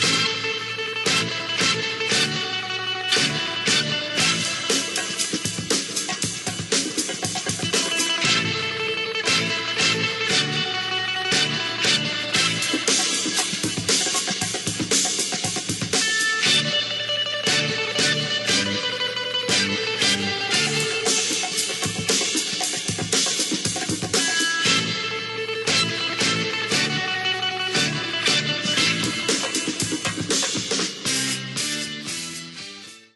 2b CLIP Apache guitar